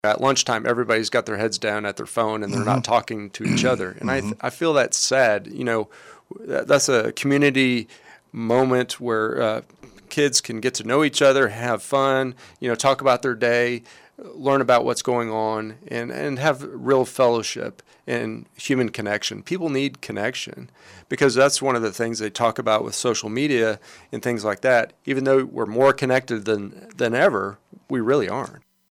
With the Kansas Legislative session beginning next week in Topeka, State Representative Steven Howe, District 71 joined in on the KSAL Morning News Extra with a look at a range of issues facing the state.